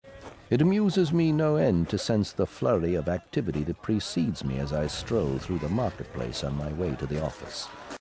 In its audio dramatization, Feltipern Trevagg is voiced by Alan Oppenheimer.